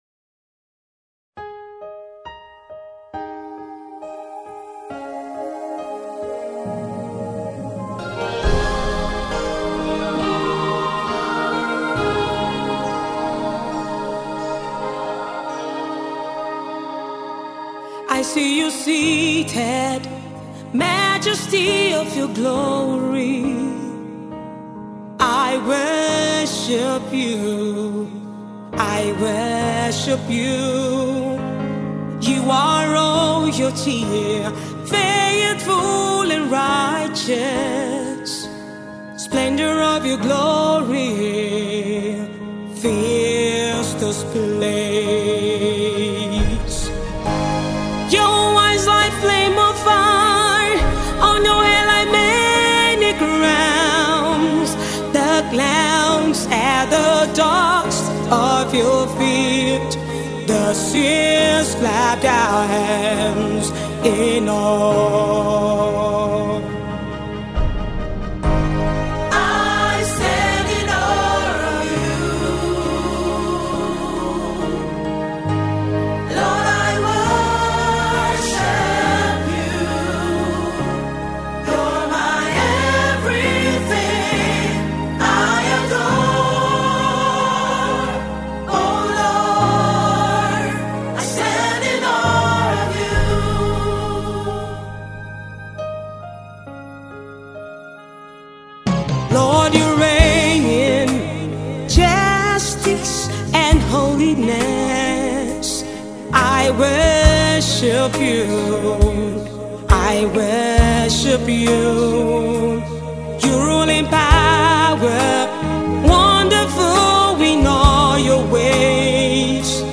soul-stirring ballad